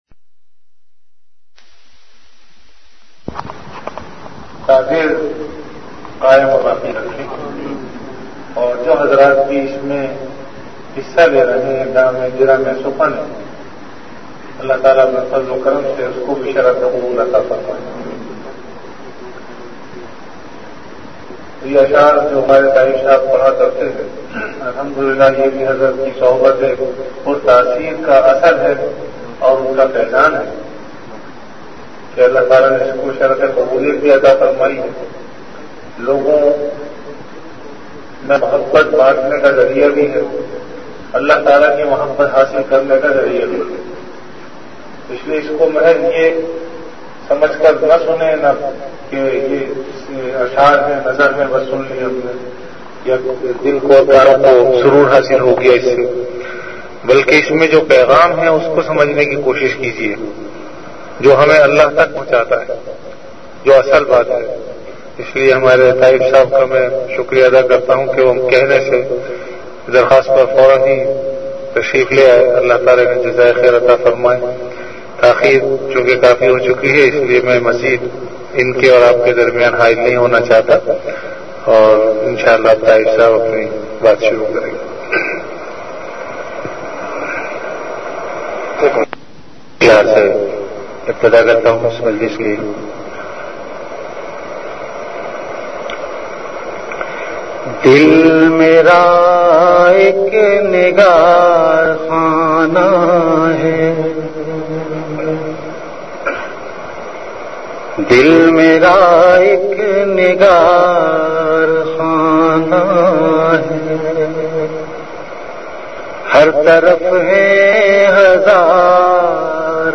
Category Ashaar
Venue Home Event / Time After Isha Prayer